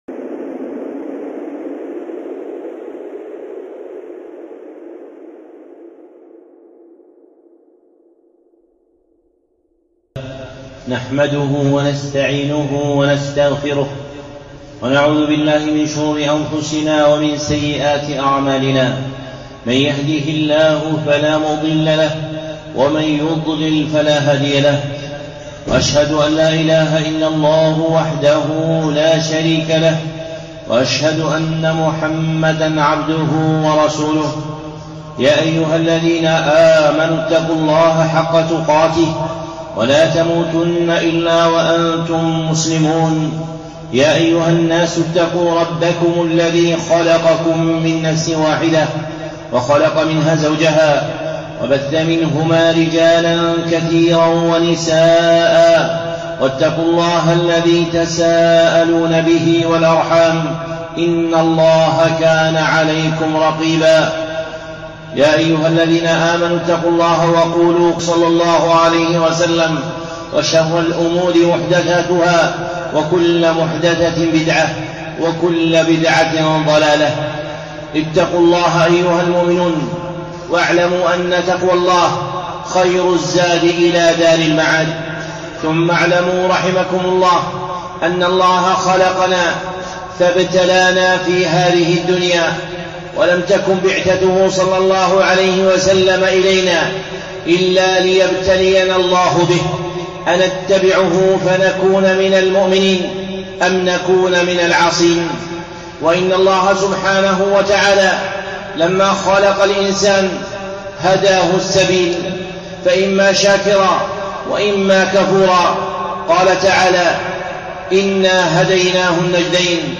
خطبة (من صفات الأبرار المتقين)
الخطب المنبرية